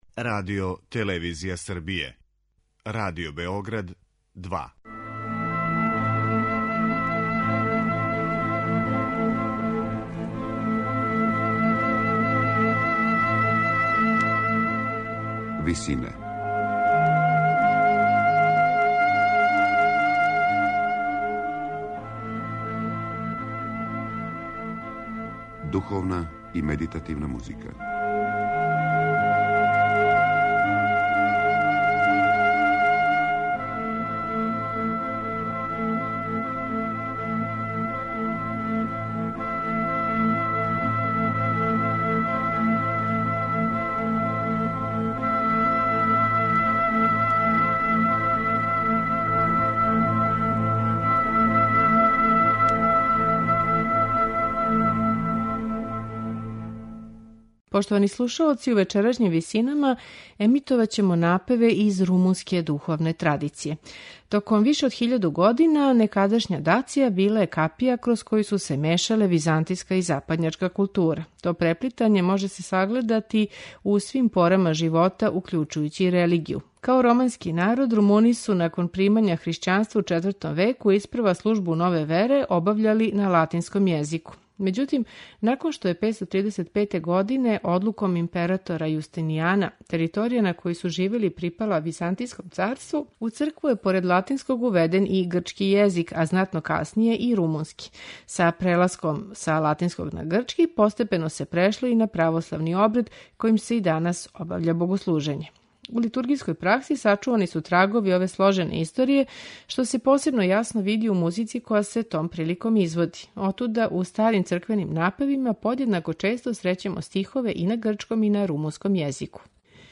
Румунска духовна музика
Ова мешавина језика и музичких стилова видљива је и у румунским литургијским напевима. У вечерашњим Висинама емитоваћемо седам напева из ове богате и шаролике традиције.
камерни хор